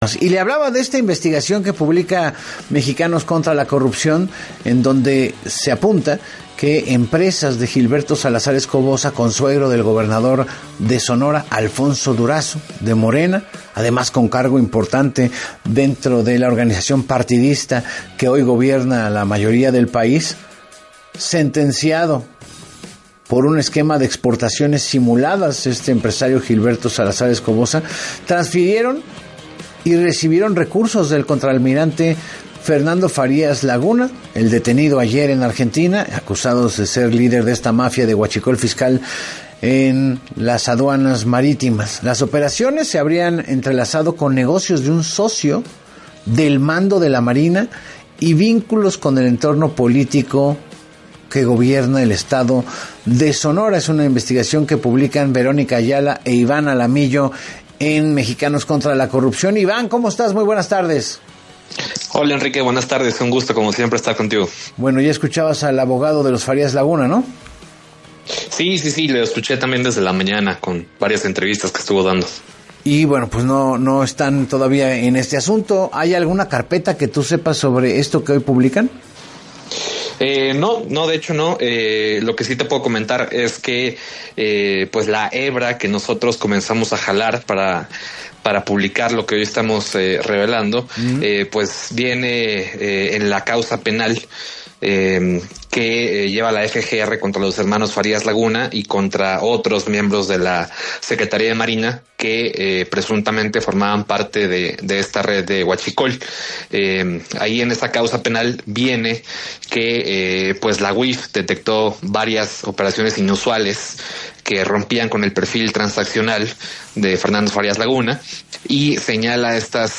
En entrevista para “Así Las Cosas con Enrique Hernández Alcázar”